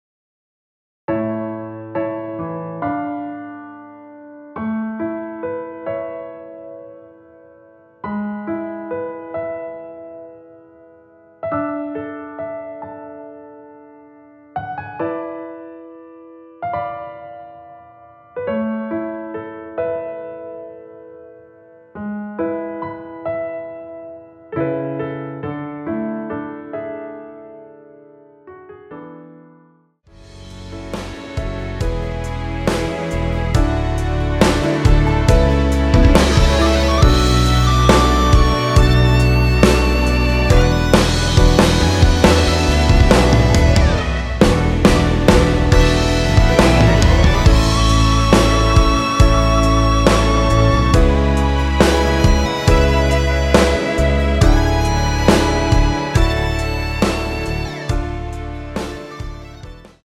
노래 들어가기 쉽게 전주 1마디 만들어 놓았습니다.(미리듣기 확인)
원키에서(+5)올린 MR입니다.
앞부분30초, 뒷부분30초씩 편집해서 올려 드리고 있습니다.
중간에 음이 끈어지고 다시 나오는 이유는